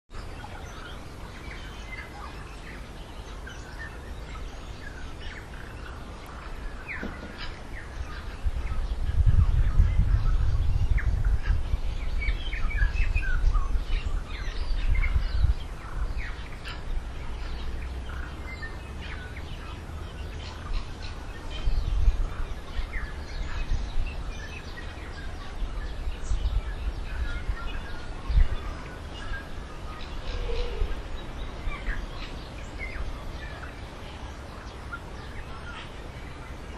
Those of you who live in the lower part of Houghton Valley will be once again surrounded by the continuous sounds of tui as they call to attract mates or fight off competitors.
Tui-1.mp3